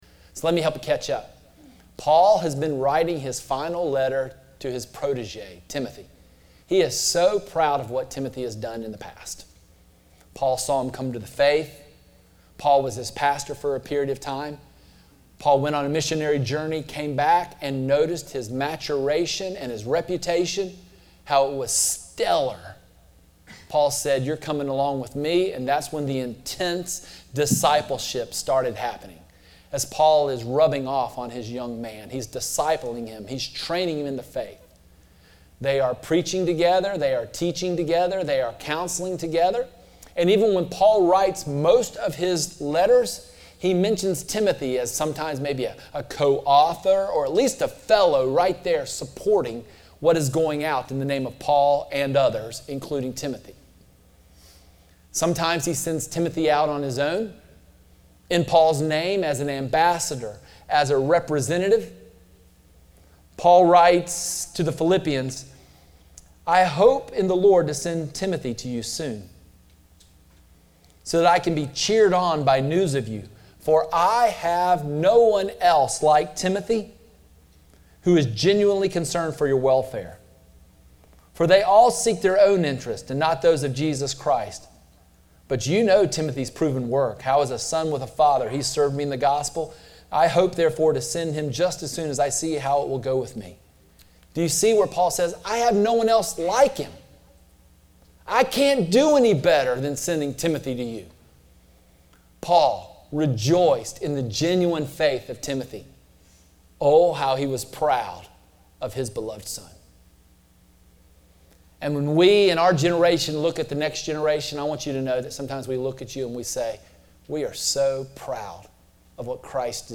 Horizon Church Sermon Audio